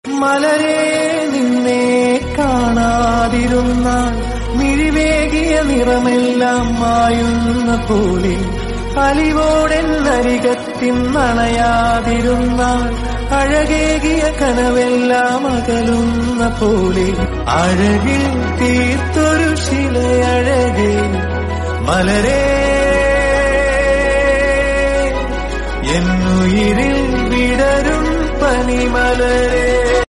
With its gentle and deep melody